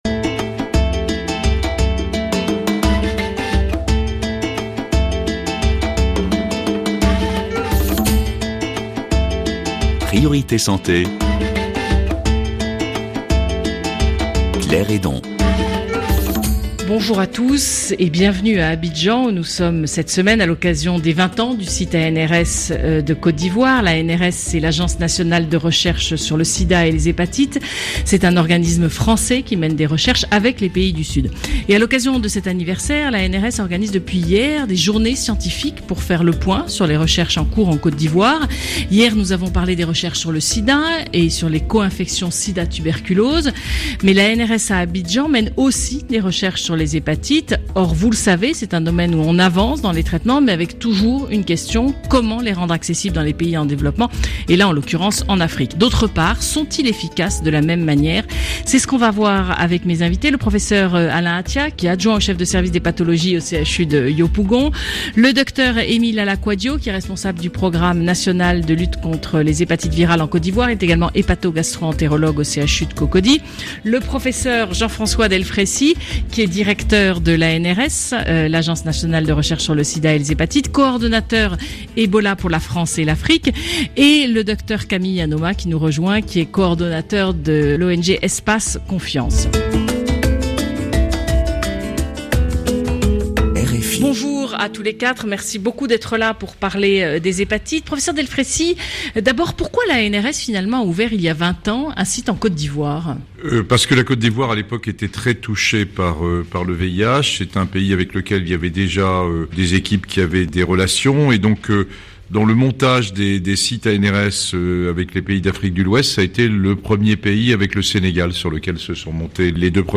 Émission enregistrée à Abidjan, à l’occasion des Journées annuelles du site ANRS de Côte d’Ivoire (Agence nationale de recherche sur le Sida). Un nouvel essai ANRS TAC va permettre de faire un point sur la prise en charge des hépatites en Afrique, et d’évaluer pour la première fois sur le continent l’efficacité des nouvelles molécules contre l’hépatite, de nouveaux traitements très chers, mais qui permettent de guérir de la maladie.